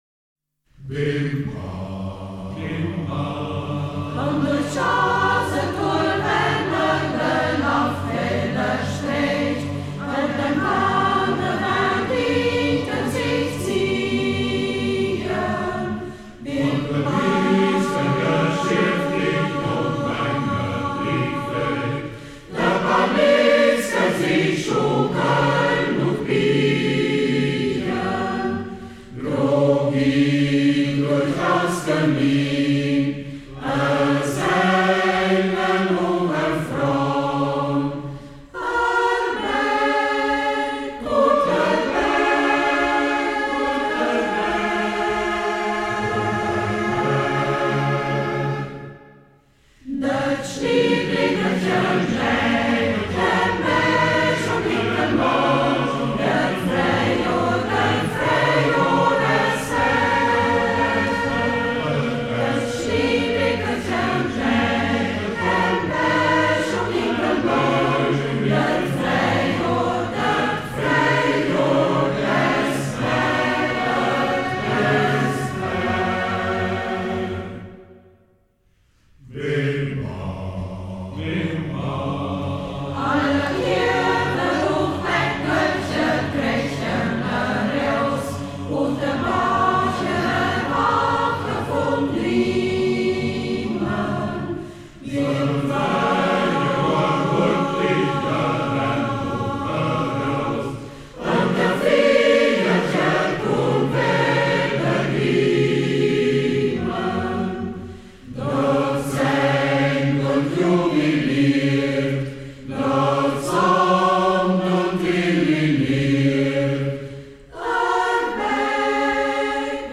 Heltauer Liedertafel • 2006 • Umgangssächsisch • 4:05 Minuten • Herunterladen
Ortsmundart: Schäßburg